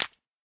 ignite.ogg